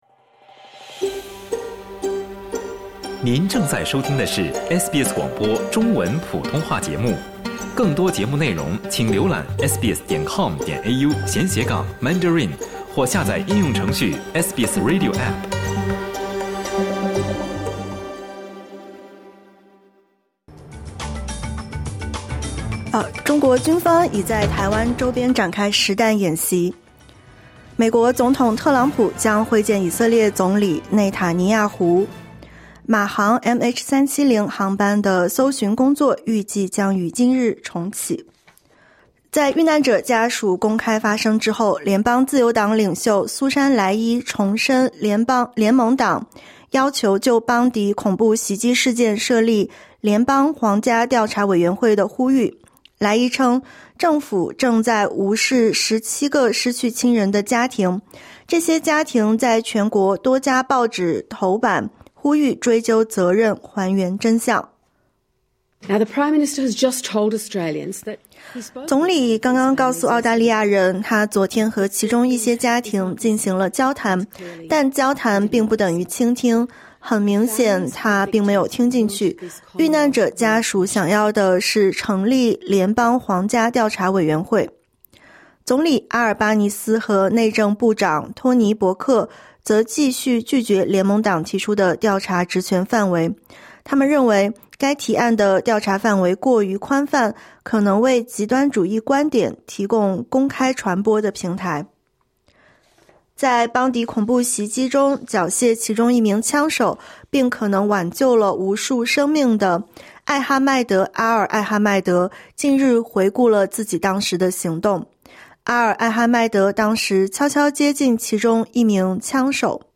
SBS 早新闻（2025年12月30日）
SBS Mandarin morning news Source: Getty / Getty Images